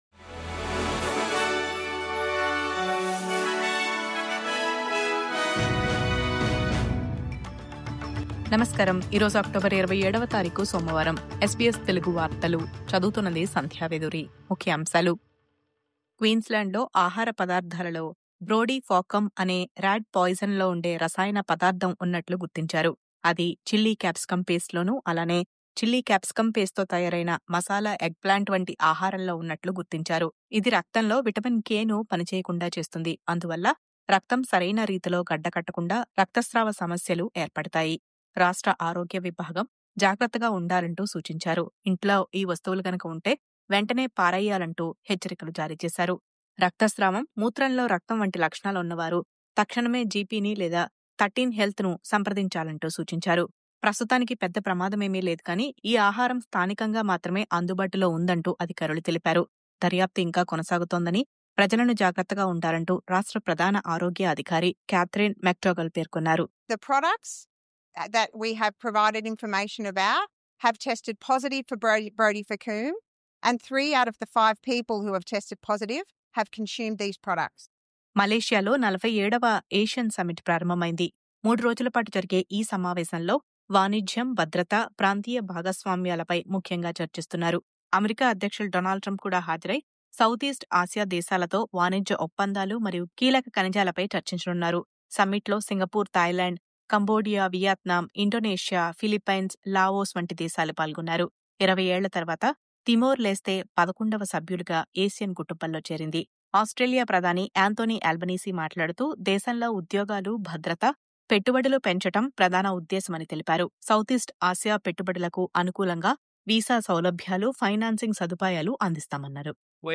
News update: క్వీన్‌స్ల్యాండ్‌లో ర్యాట్‌పాయిజన్ హెచ్చరికలు; మైక్రోసాఫ్ట్‌పై ACCC చర్యలు; మలేషియాలో 47వ ASEAN సమ్మిట్ ప్రారంభం..